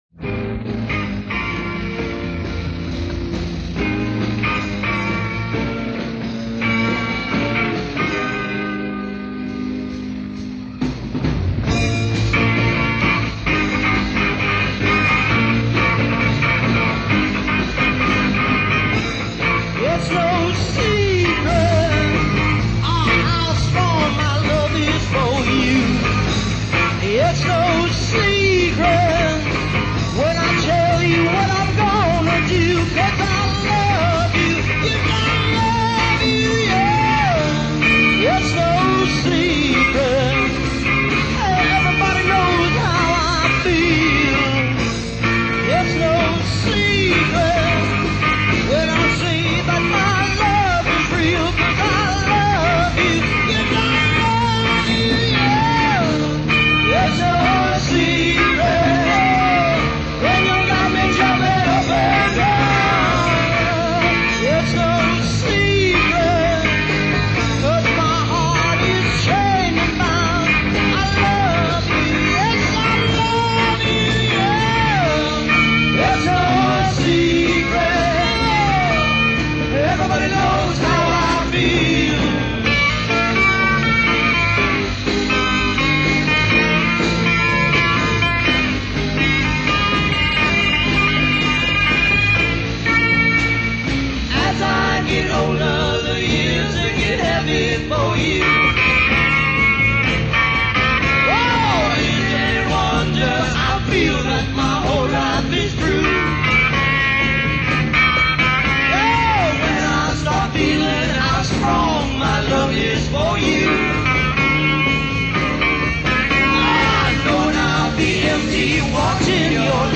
Live at The Calliope Warehouse
Folk-Rock ensemble available for your next dance